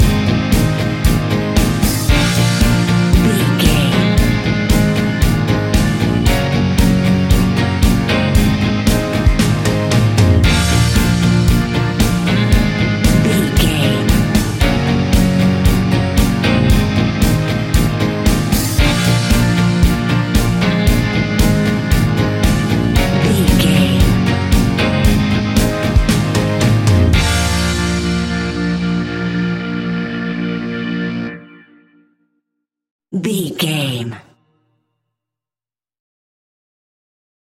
Fast paced
Mixolydian
B♭
pop rock
energetic
uplifting
acoustic guitars
drums
bass guitar
electric guitar
piano
organ